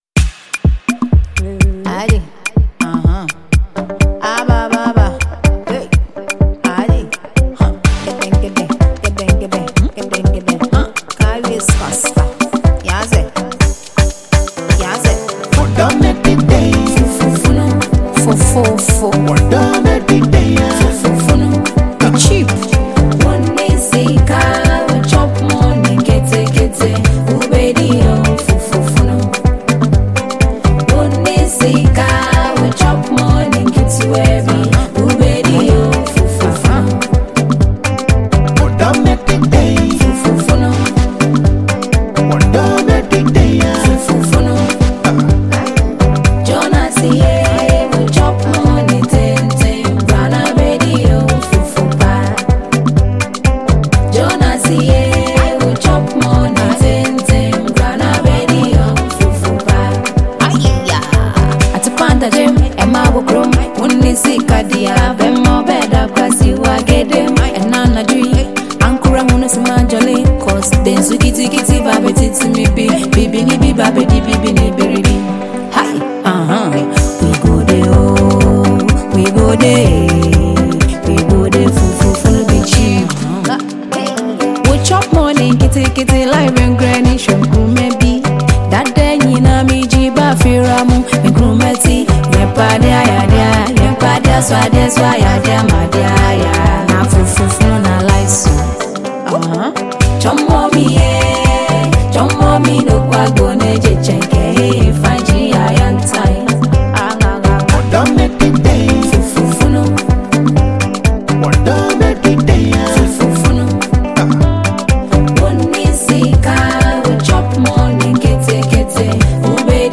Ghana Music
Ghanaian songstress